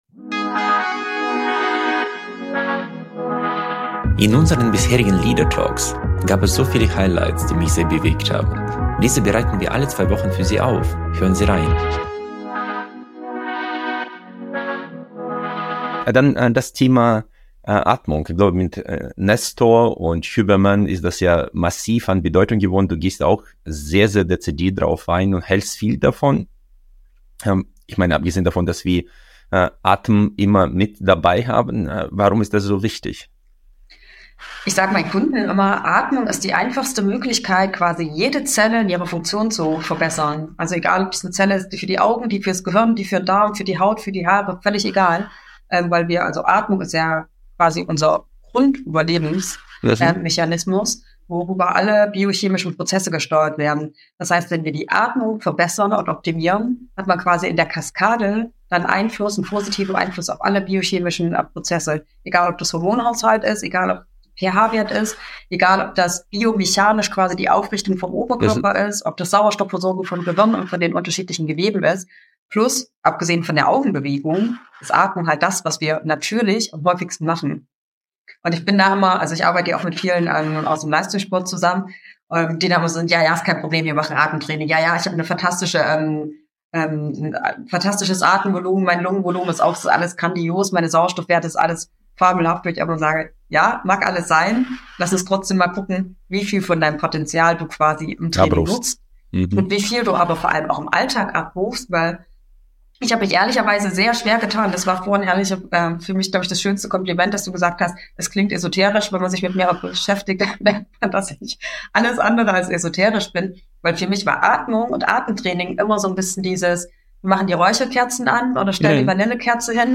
Ein Gespräch über Atemtechnik mit Alltagsbezug und zwei schnell umsetzbare Atemübungen.